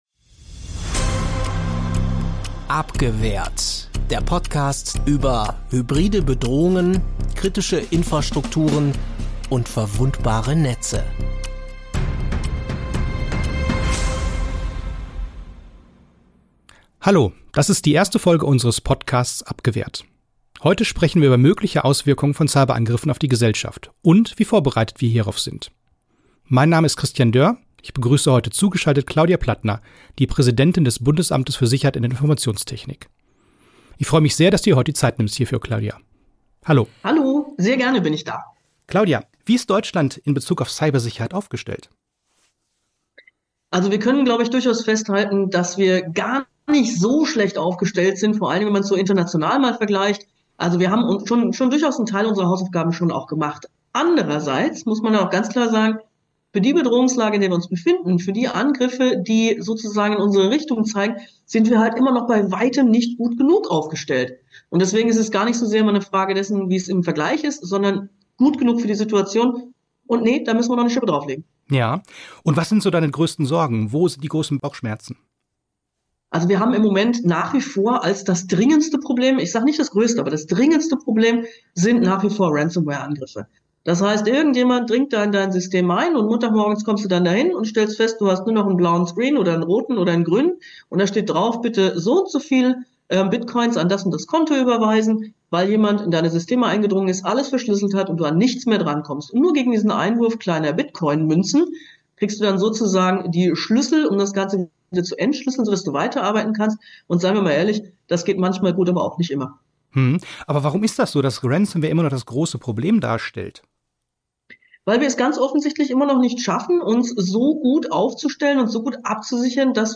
Interview mit Claudia Plattner, Präsidentin des Bundesamtes für Sicherheit in der Informationstechnik (BSI)